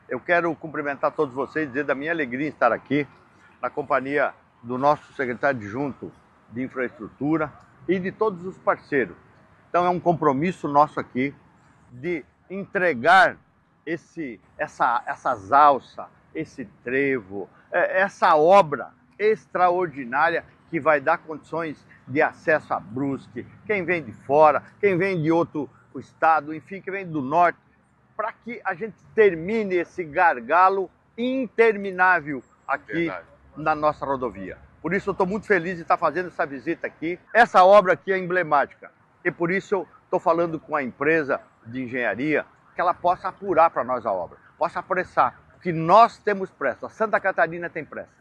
Nesta quinta-feira (23), o governador Jorginho Mello visitou o canteiro de obras para acompanhar de perto e falou sobre o andamento dos trabalhos: